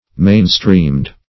mainstreamed - definition of mainstreamed - synonyms, pronunciation, spelling from Free Dictionary
mainstreamed \main"streamed`\ adj. (Education)